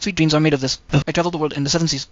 mimic3 audio prompts
en_US_cmu_arctic_aup.wav